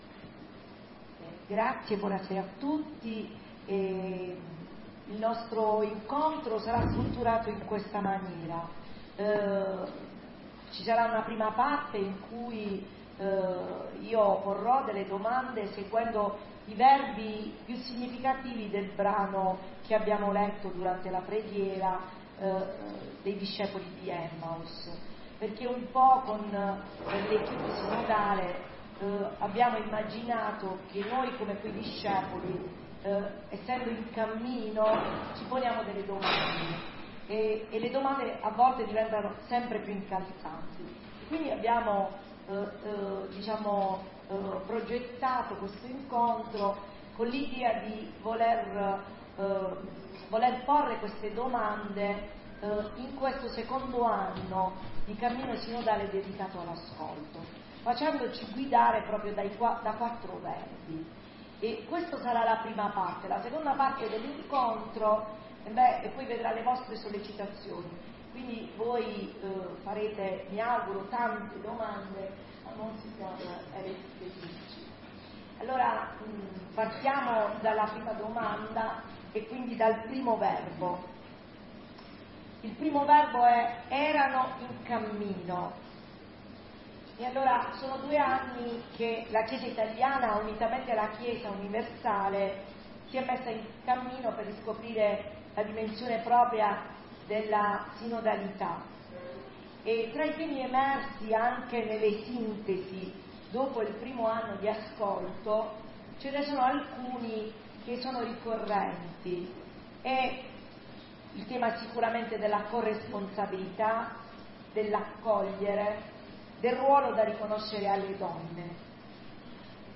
Cammino Sinodale – Assemblea Diocesana
Il cammino sinodale ha coinvolto l’intera comunità Diocesana con un momento assembleare, dove è stato possibile confrontarsi con Mons. Erio Castellucci, Vice Presidente della Conferenza Episcopale Italiana e Presidente del Comitato nazionale del cammino sinodale.